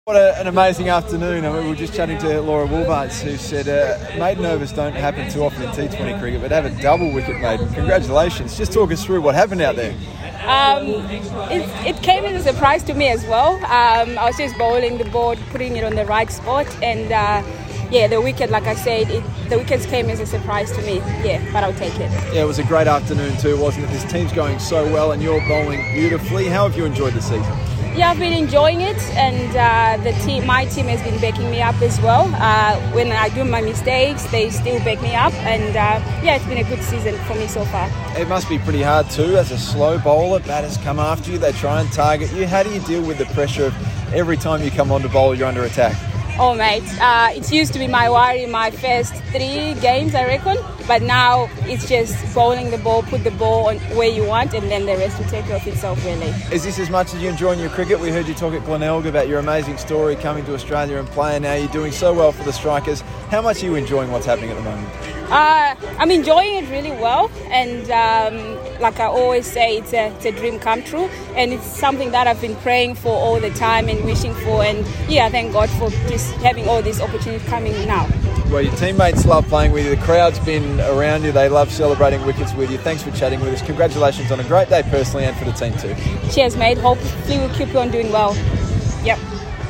speaking to media after her double wicket maiden.